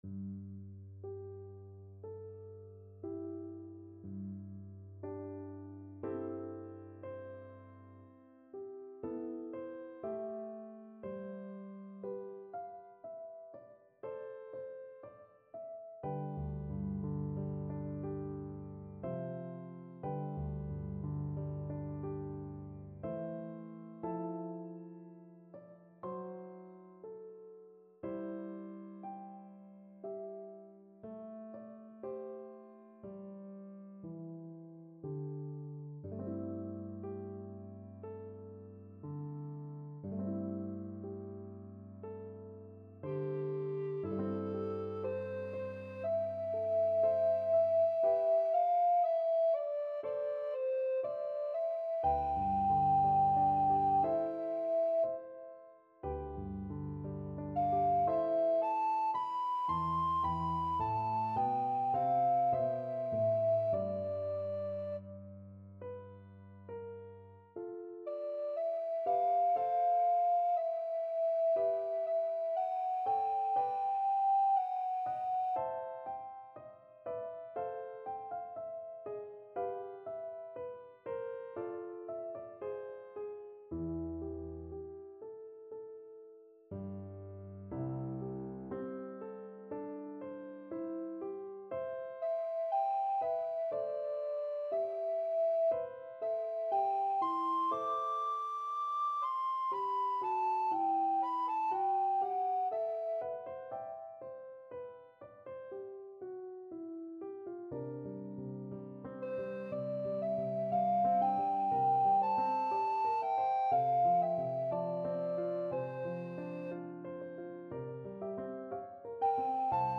Free Sheet music for Alto (Treble) Recorder
Alto Recorder
C major (Sounding Pitch) (View more C major Music for Alto Recorder )
= 60 Molto lento e ritenuto
Classical (View more Classical Alto Recorder Music)